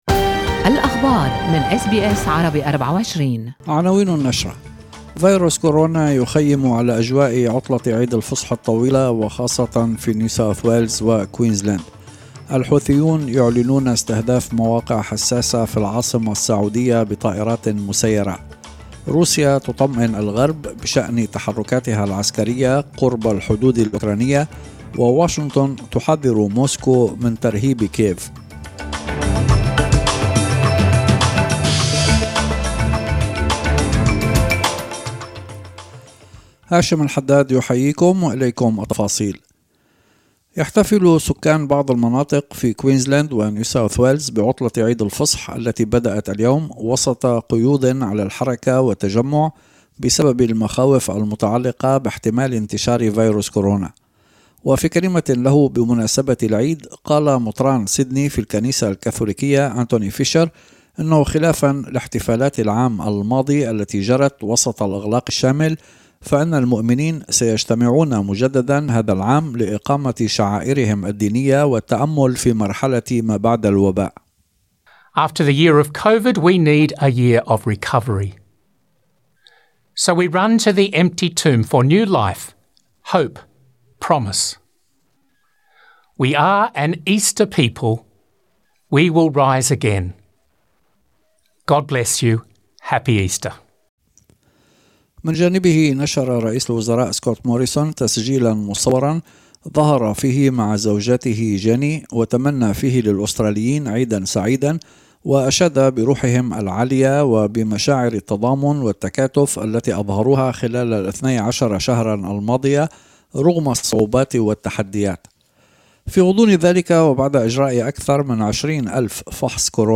نشرة أخبار المساء 2/4/2021